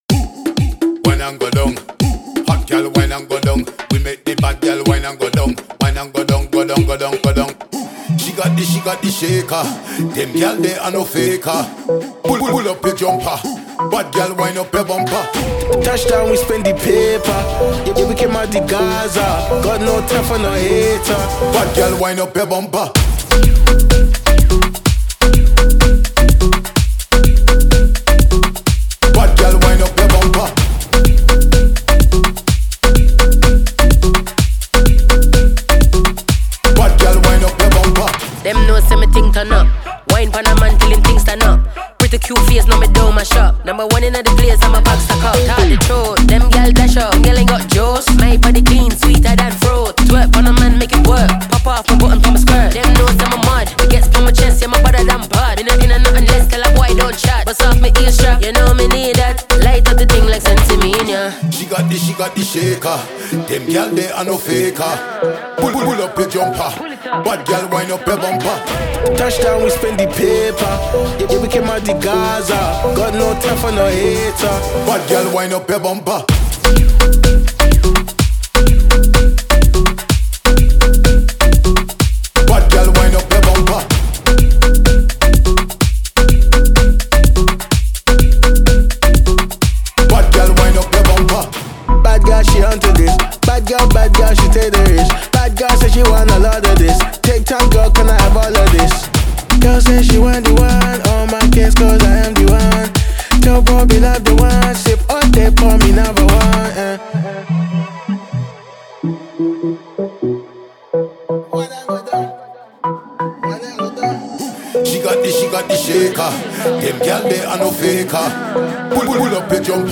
это зажигательный трек в жанре грайм и регги